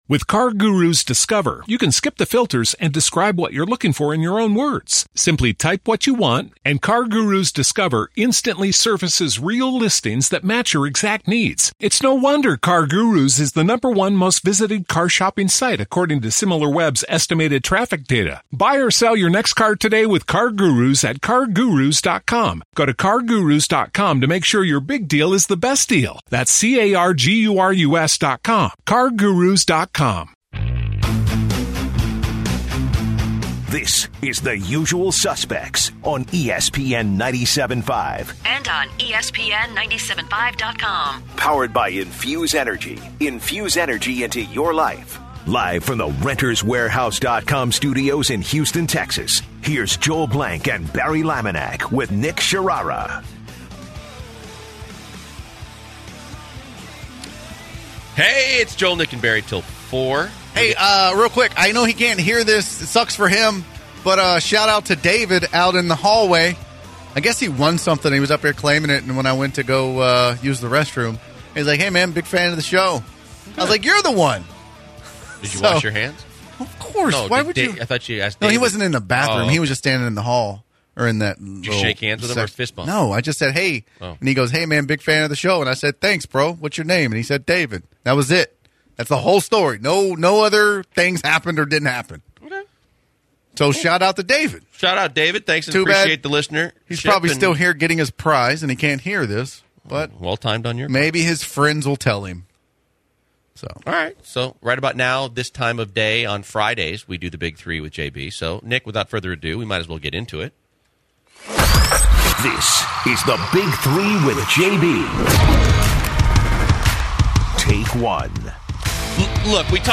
They also talk about adding a reliable pitcher and the value of the pitching rotation. Special Guest Robert Horry joins the show and talks about ways to find motivation.